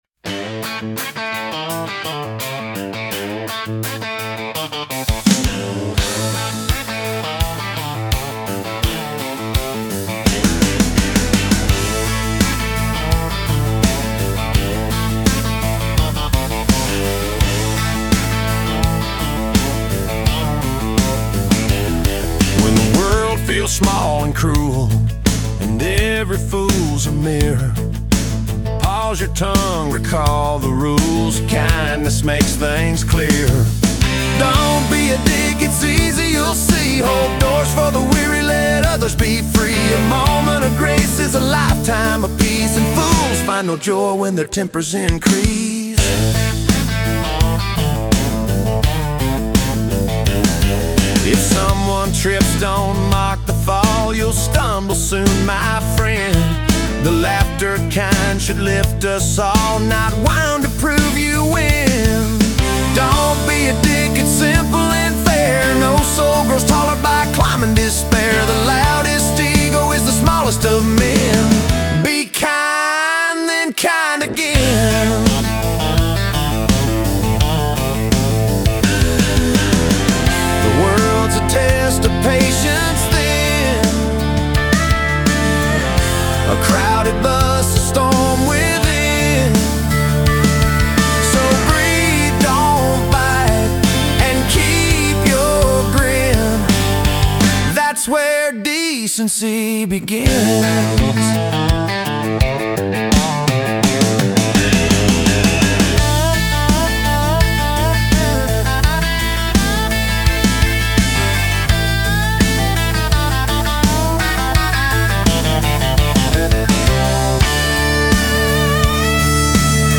General Hymns
Country